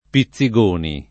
Pizzigoni [ pi ZZ i g1 ni ] cogn.